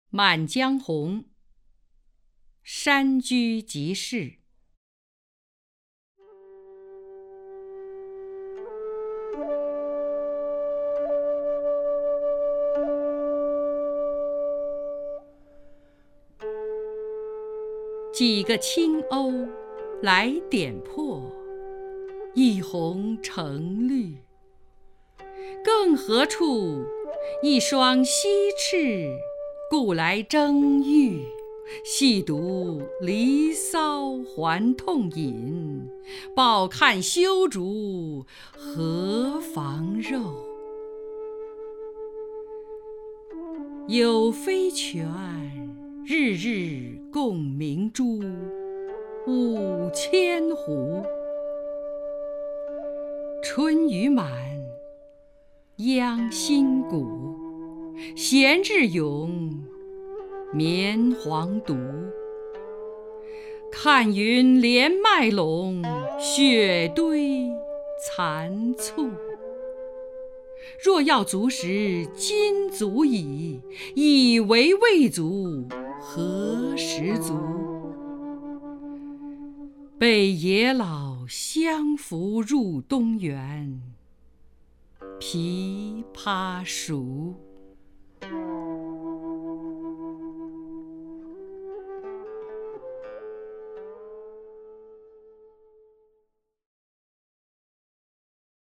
张筠英朗诵：《满江红·山居即事》(（南宋）辛弃疾)　/ （南宋）辛弃疾
名家朗诵欣赏 张筠英 目录